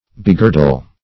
Begirdle \Be*gir"dle\, v. t.